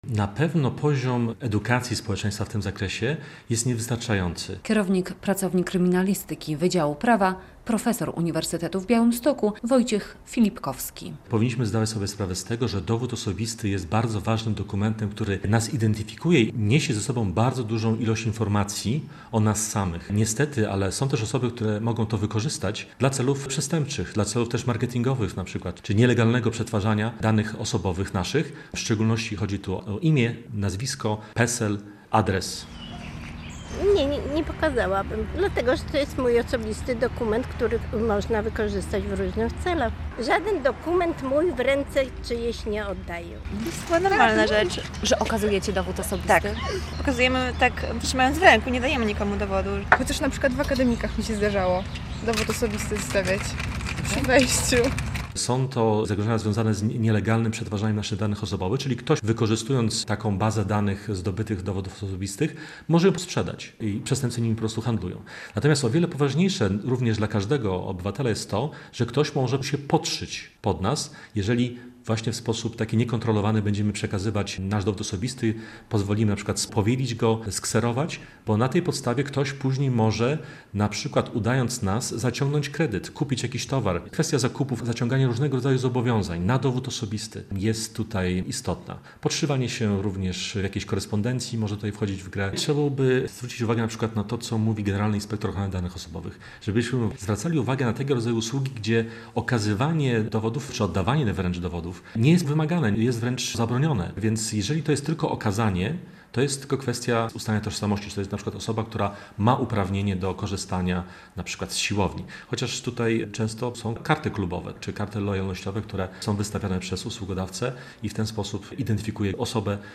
Białostoczanie zapewniają, że ostrożnie udostępniają swój dowód osobisty, choć przyznają, że pozwalali np. na kserowanie dokumentu.